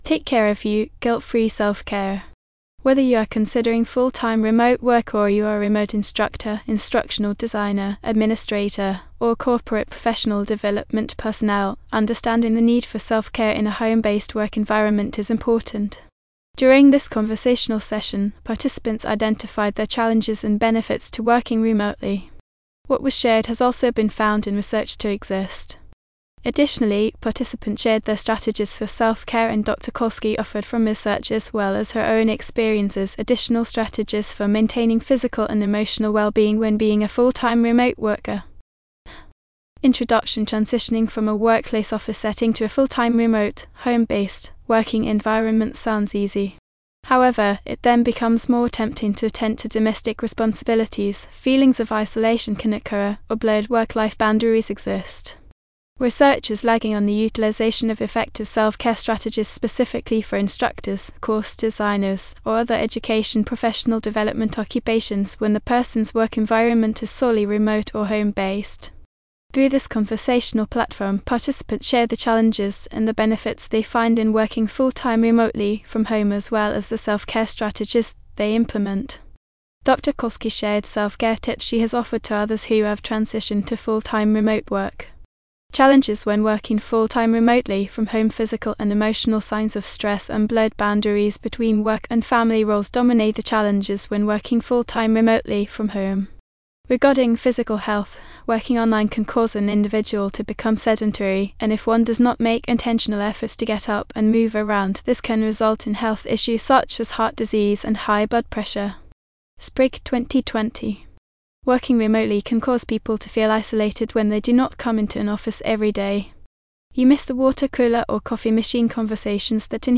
During this conversational session, participants identified their challenges and benefits to working remotely.